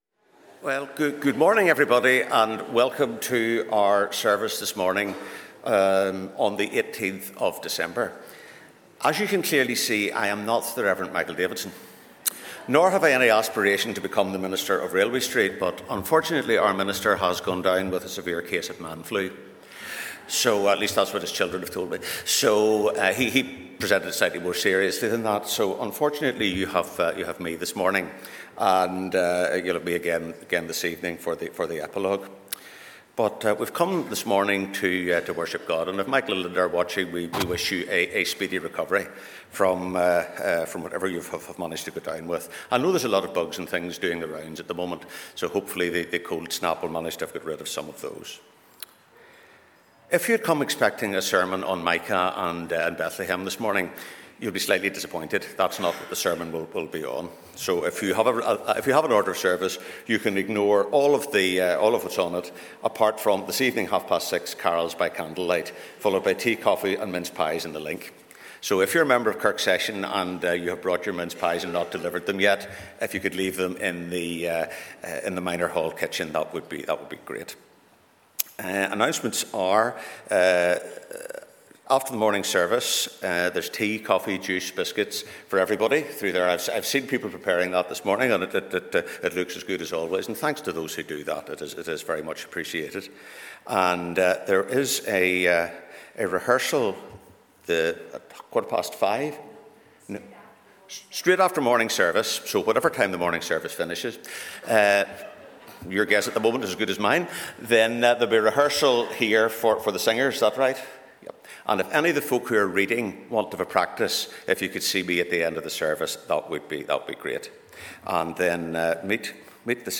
In our sermon this morning we are going to think about what Joseph's experience can teach us today as we consider a: